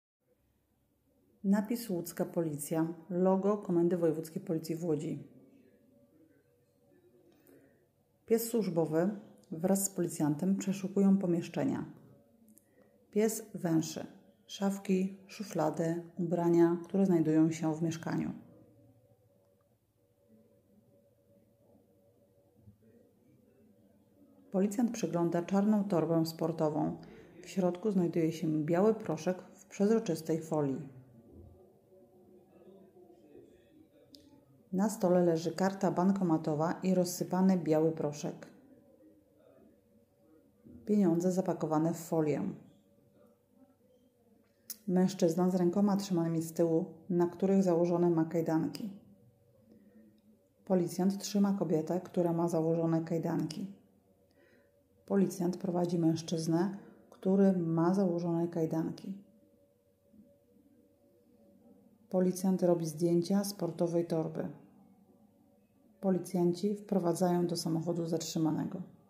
Nagranie audio Audiodeskrypcja do tekstu